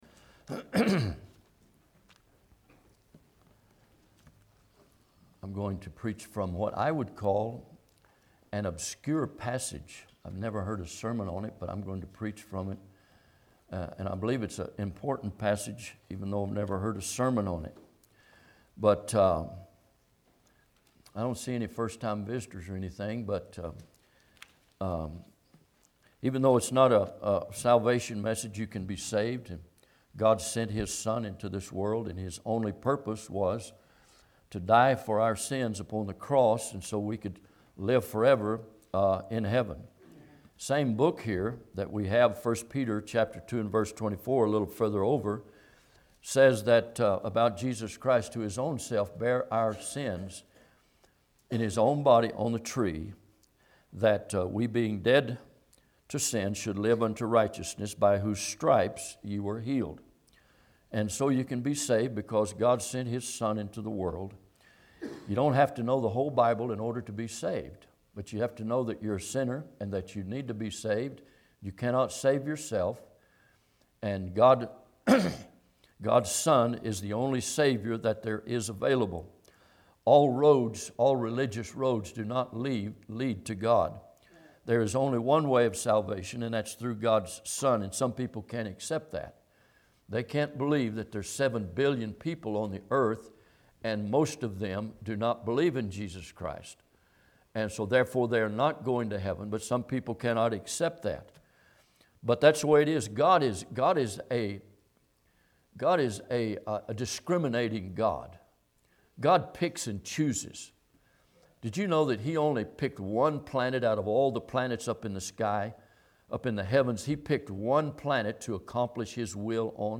I Peter 2:1-8 Service Type: Sunday am Bible Text